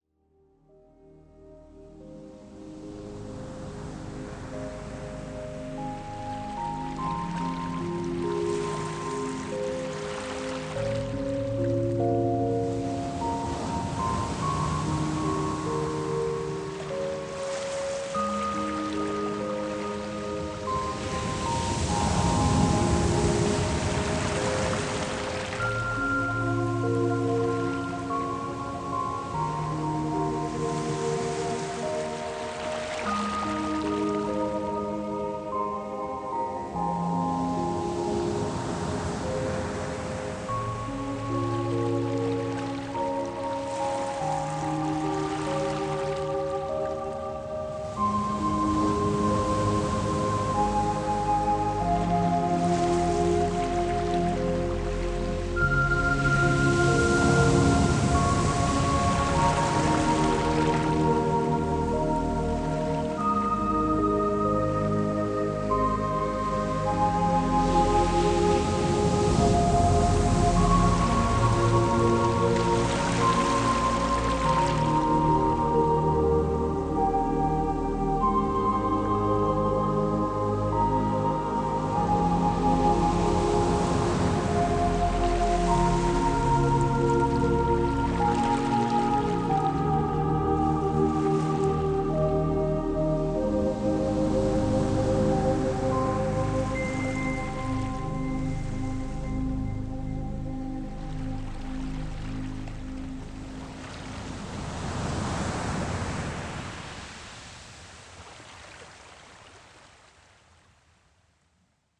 varied, nice melodies and sounds from nature